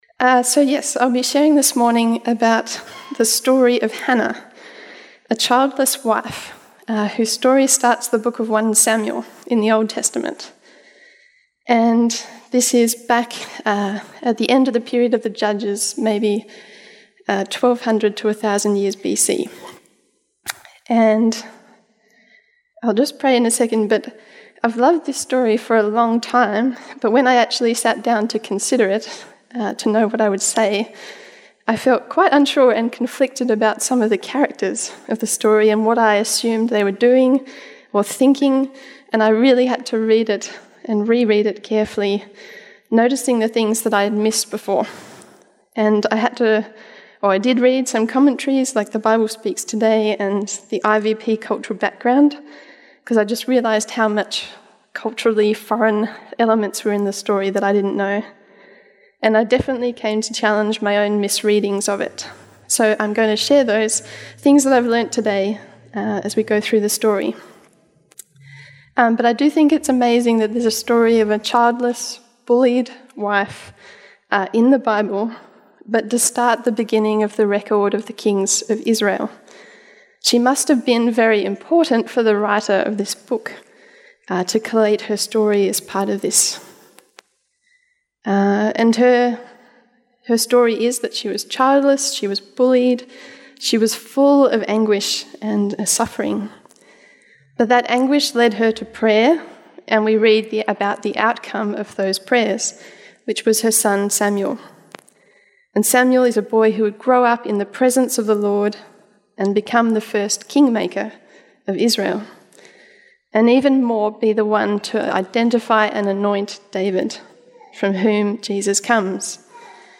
Summerhill Baptist Church Sermons